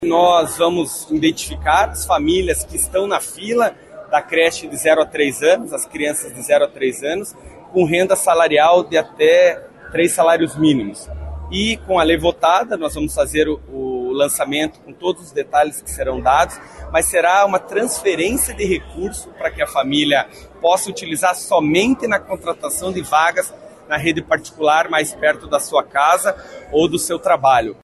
Durante entrevista concedida para a imprensa na sede do Legislativo Municipal, nesta segunda-feira (3), o prefeito Eduardo Pimentel (PSD) falou sobre a iniciativa.